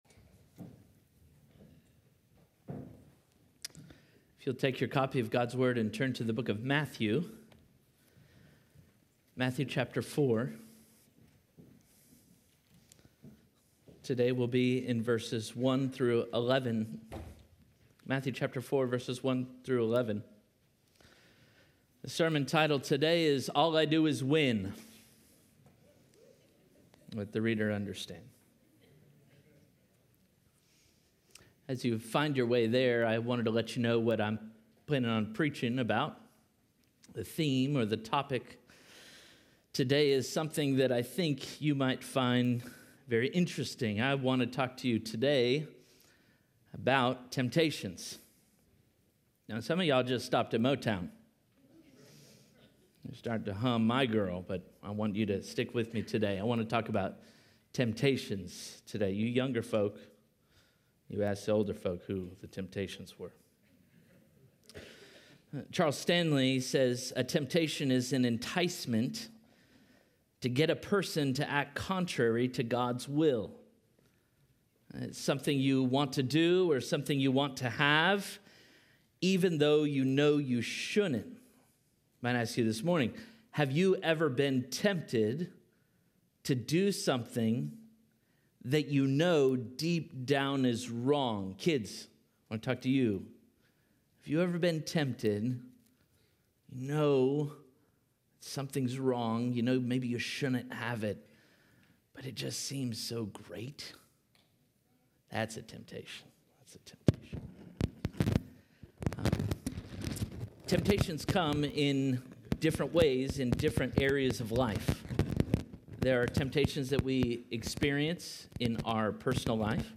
Parkway Sermons All I Do is Win!